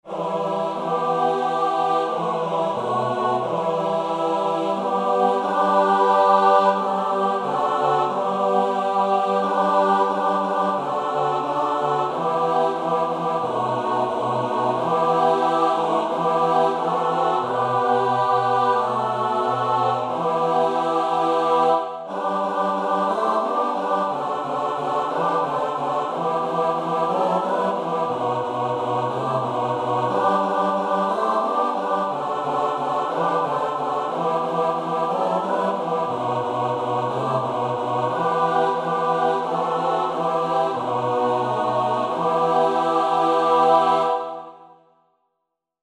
ЖАНР: Колядки та щедрівки
Комп’ютерне відтворення нот (PDF, mp3):